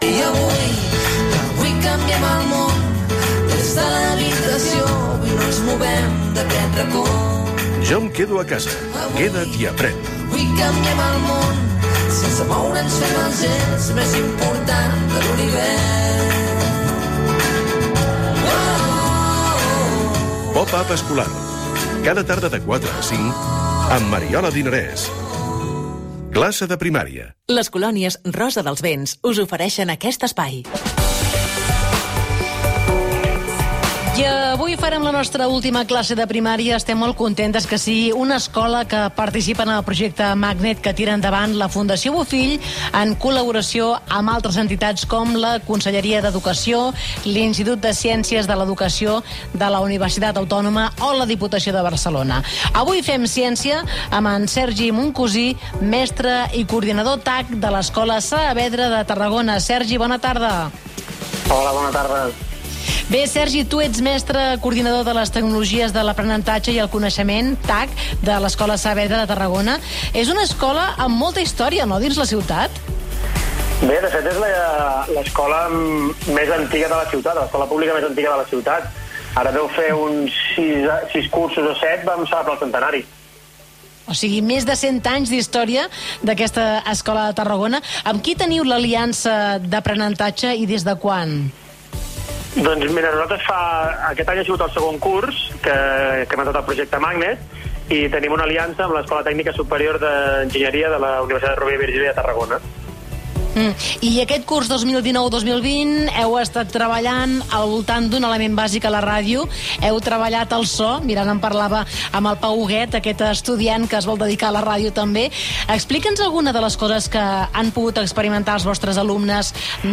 Aquest projecte va ser un dels temes protagonistes del programa POPAP Escolar de Catalunya Ràdio que es va emetre de 16h a 17h el dia 10 de juny de 2020.